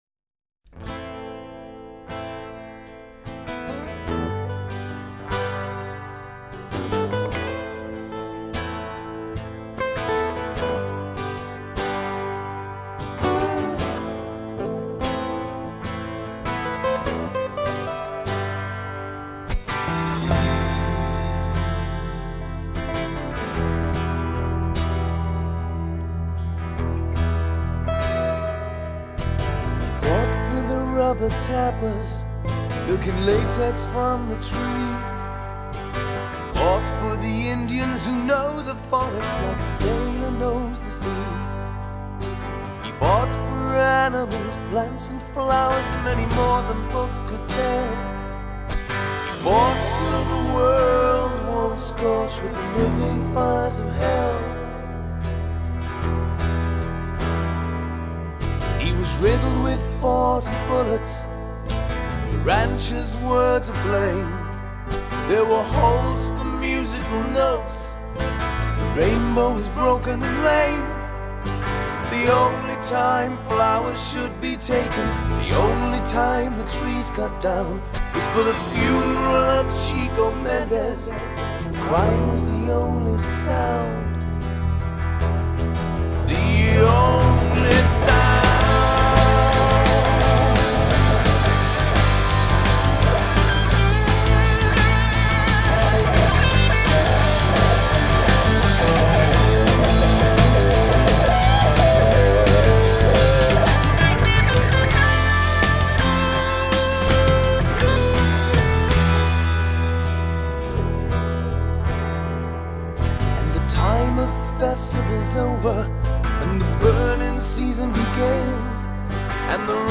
Drums
Bass
Harmonica
Violin
Backing Vocals
Saxophone